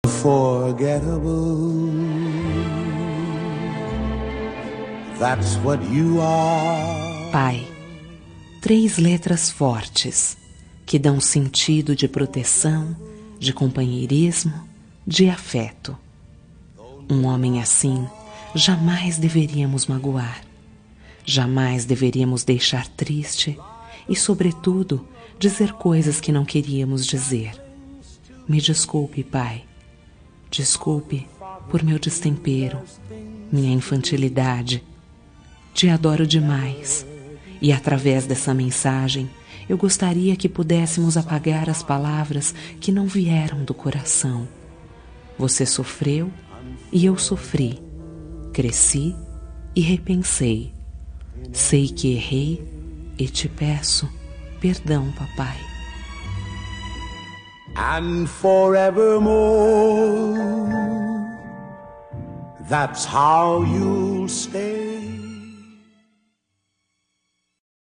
Reconciliação Familiar – Voz Feminina – Cód: 088723 – Pai